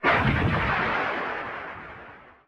mine impact.mp3